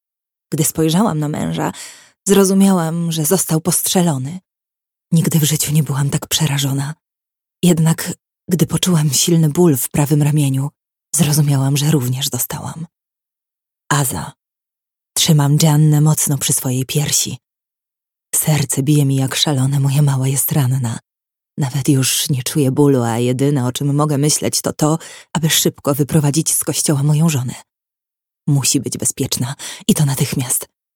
Dyplomowana aktorka, która zrealizowała wiele projektów dubbingowych.
Posłuchaj z melodią w tle
Próbka: Nagranie lektorskie